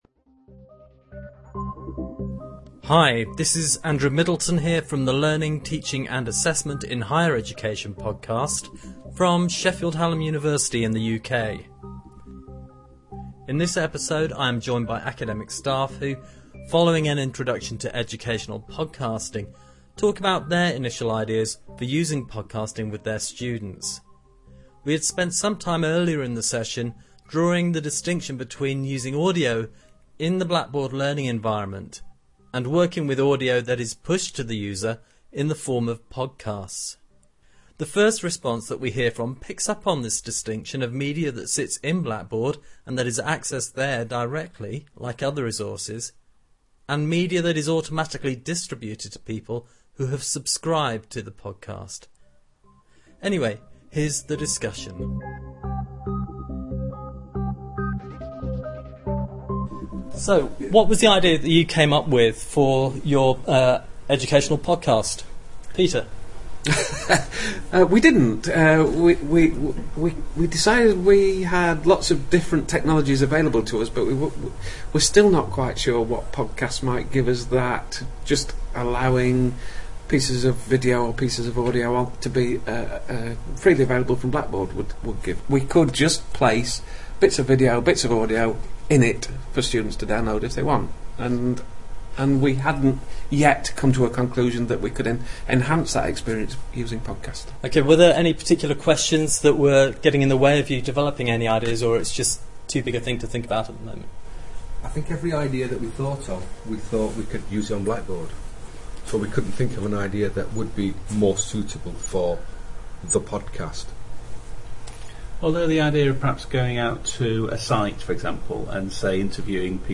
#30 Educational podcasting - a discussion